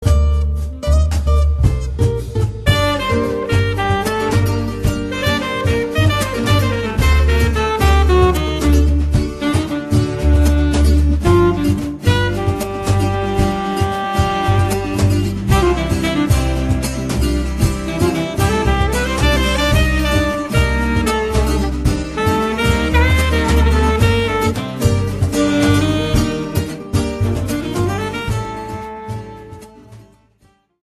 tango caboclo